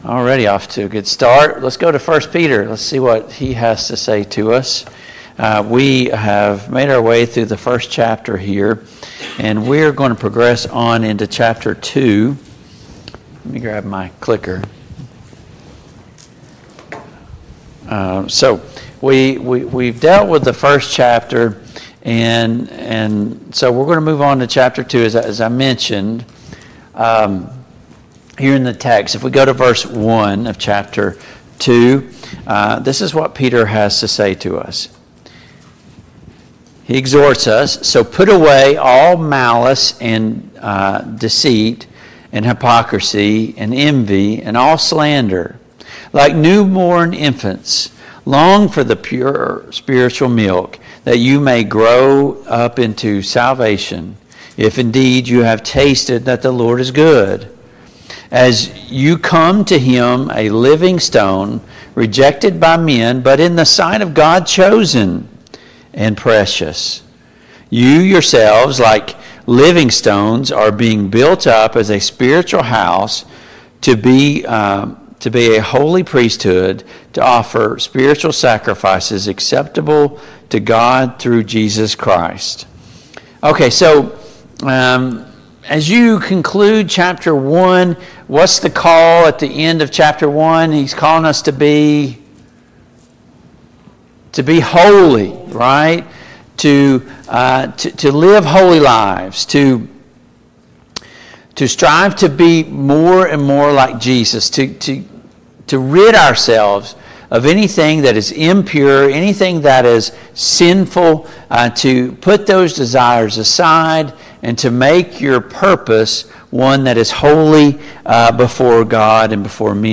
Study of James and 1 Peter and 2 Peter Passage: 1 Peter 2:1-5 Service Type: Family Bible Hour « How do we overcome when life gets hard?